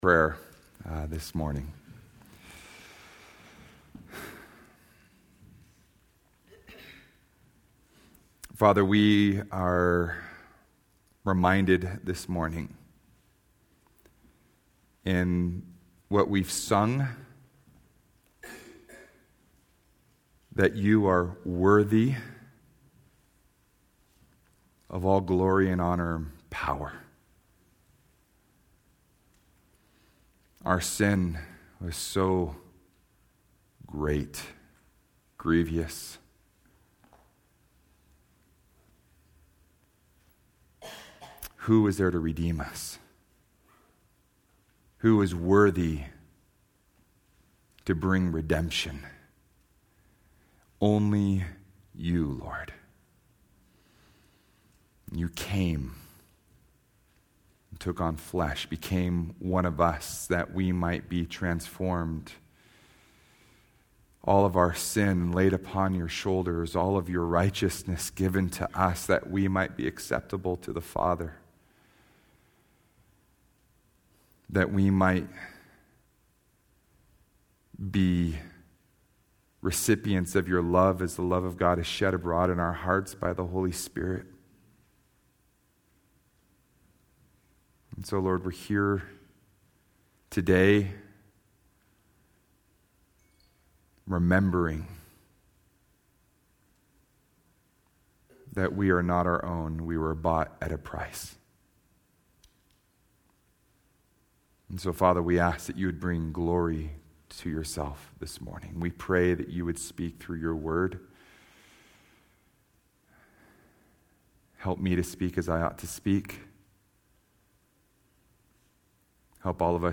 Sermons - Solid Rock Christian Fellowship